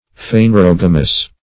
Phanerogamous \Phan`er*og"a*mous\